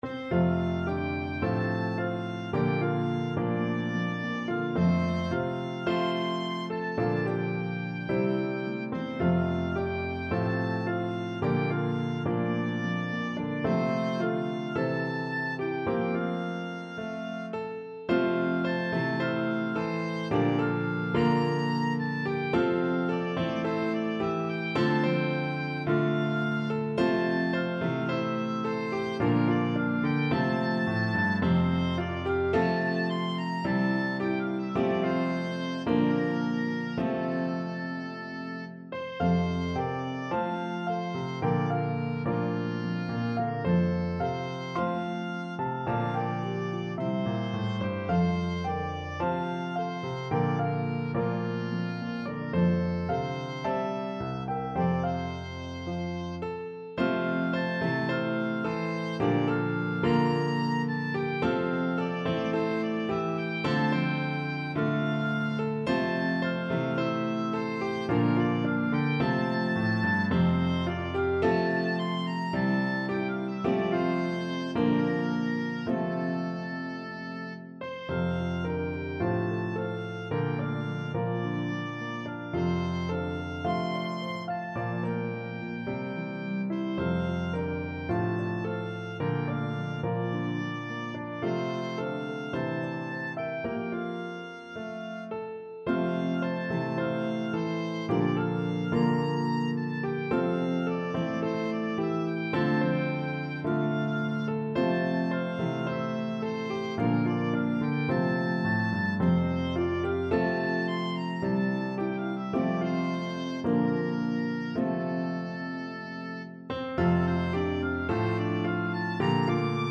pedal harp
Harp, Piano, and Oboe version